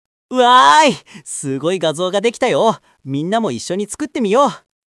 キャラクター設定と演技指定による多彩な音声生成：
-v Puck -c "元気いっぱいの子供" -d "興奮して楽しそうに" |
narration-energetic.mp3